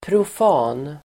Ladda ner uttalet
profan adjektiv, secular , profane Uttal: [prof'a:n] Böjningar: profant, profana Synonymer: värdslig, världslig Definition: icke-kyrklig, världslig profane adjektiv, profan Förklaring: icke-kyrklig, världslig